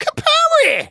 tnt_guy_kill_01.wav